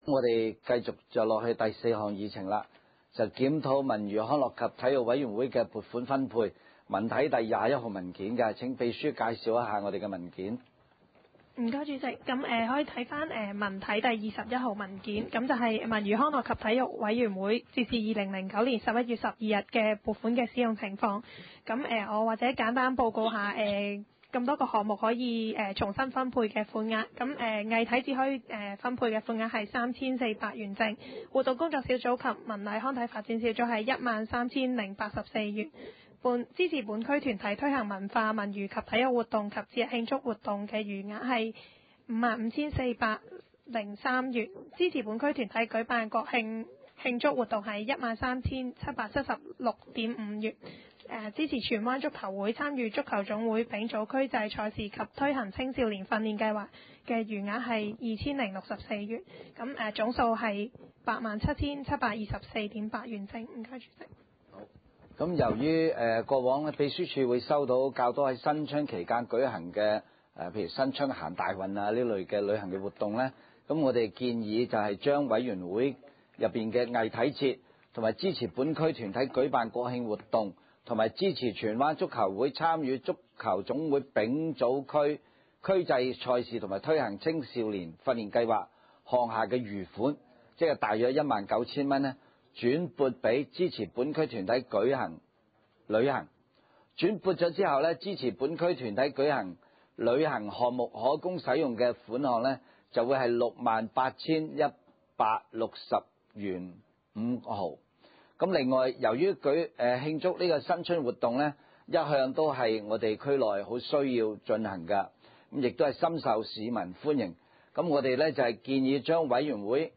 文娛康樂及體育委員會第十二次會議
荃灣民政事務處會議廳